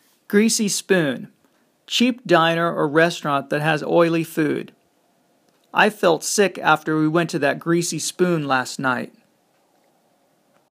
マンツーマンのレッスン担当の英語ネイティブによる発音は下記のリンクをクリックしてください。